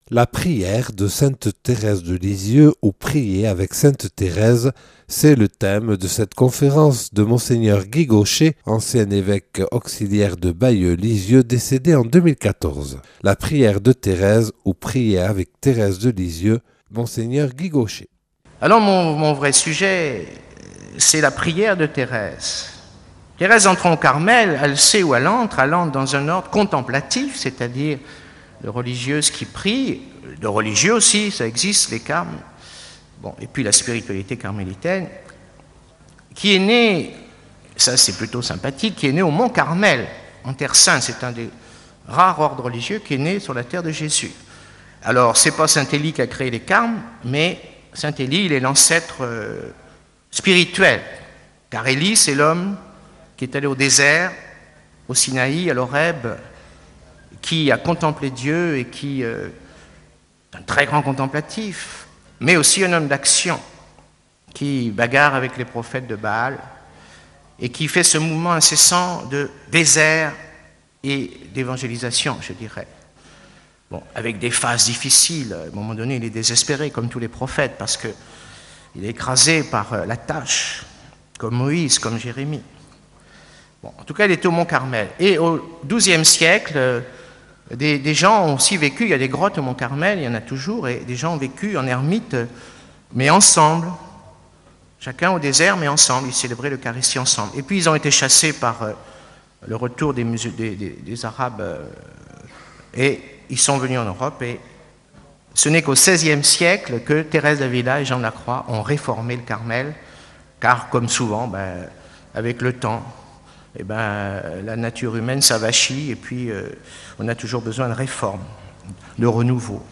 Une conférence de Mgr Guy Gauchet (+ 2014).